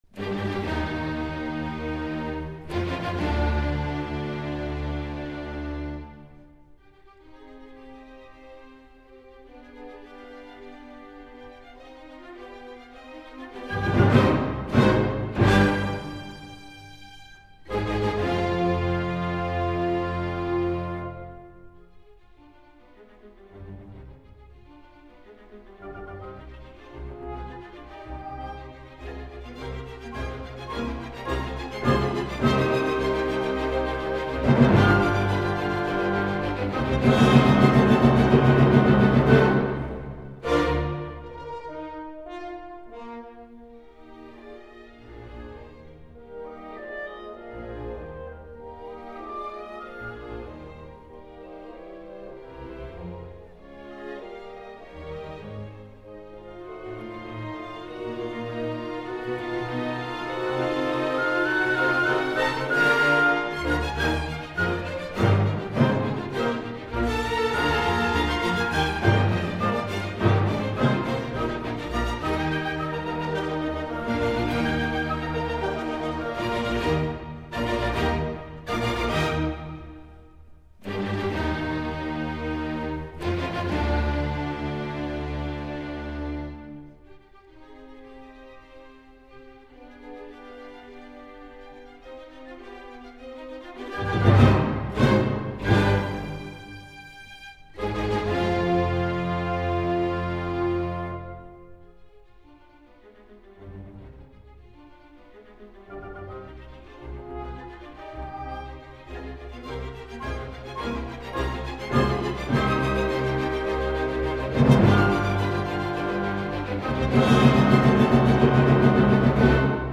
Incontro con Kent Nagano